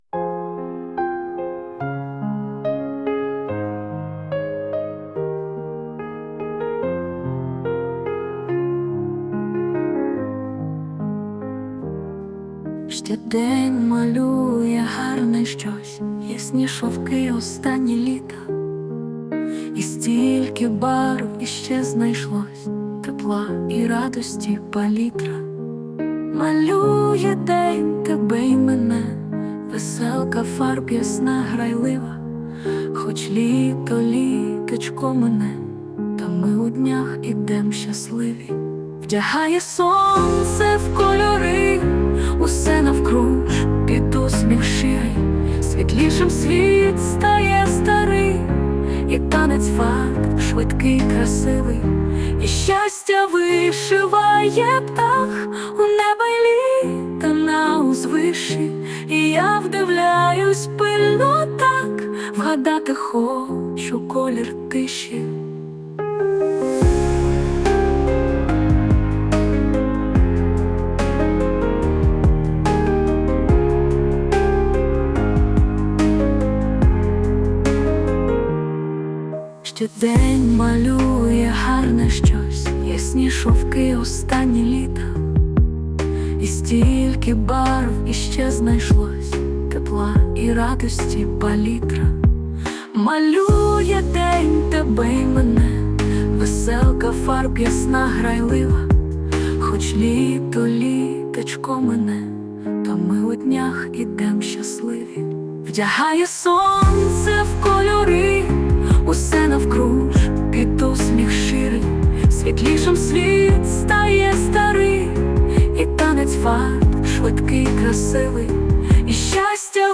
музичний супровід від ШІ СУНО
СТИЛЬОВІ ЖАНРИ: Ліричний
ВИД ТВОРУ: Пісня